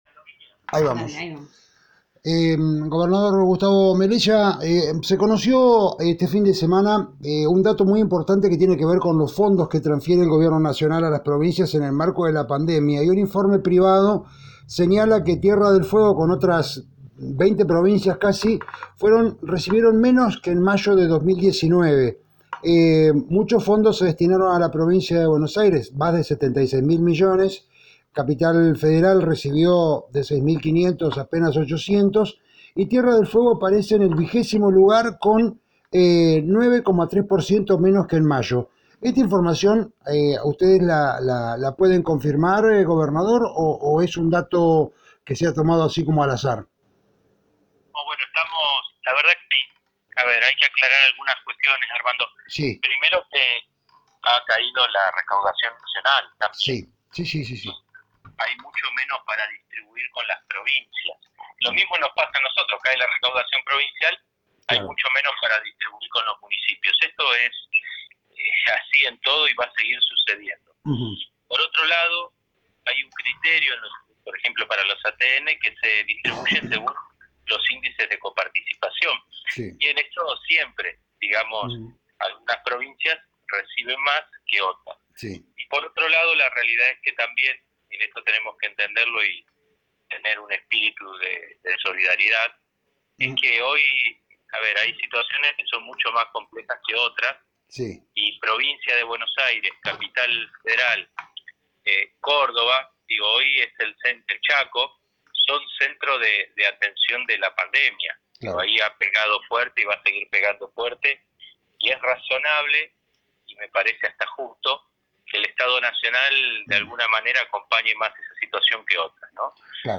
Lun 08/06/2020.- El gobernador de la provincia se refirió a varios temas, como caída de la recaudación provincial, reestructuración de la deuda de 200 millones de dolares, internas en el gobierno, relación con los intendentes, la obra del Corredor del Beagle que será modificada, actividad en las redes sociales de algunos funcionarios e integrantes de FORJA, las facturas de la Cooperativa Eléctrica que generaron malestar, continuidad de obras publicas y la apertura de la ruta 3 entre otros temas de la actualidad de Tierra del Fuego.